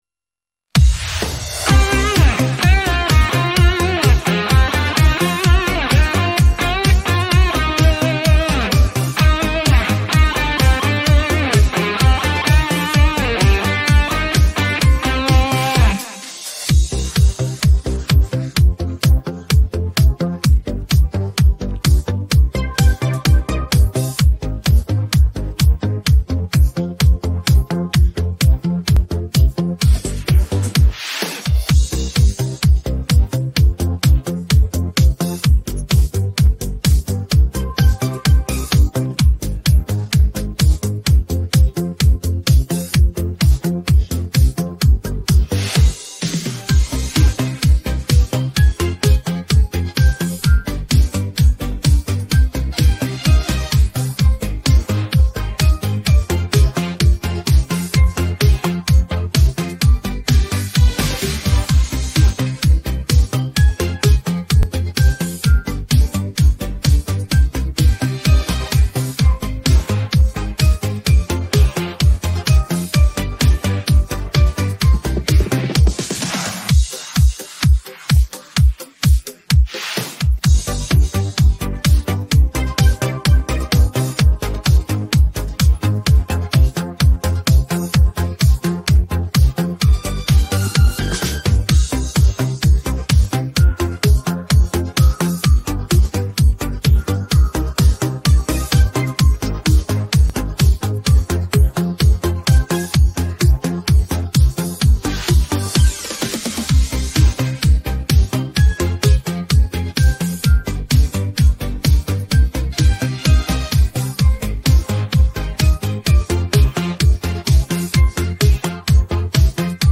українське караоке 622